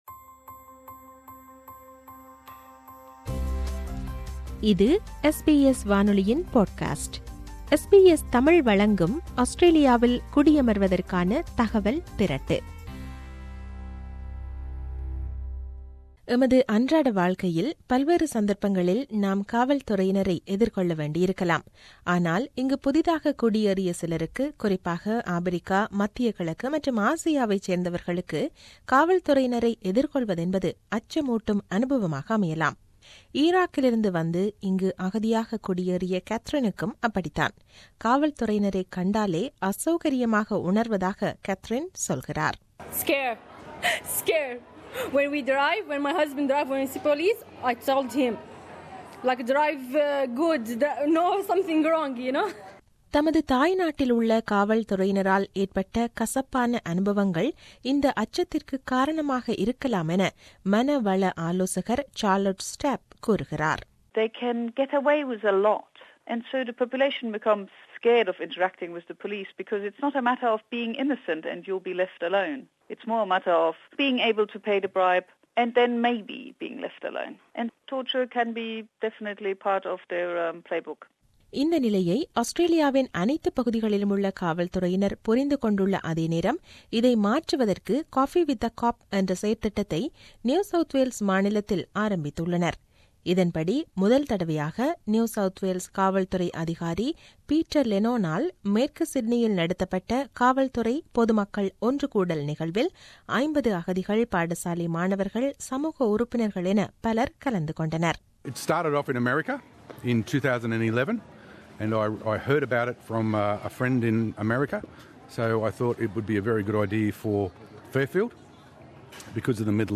ஆங்கில விவரணத்தைத் தமிழில் தருகிறார்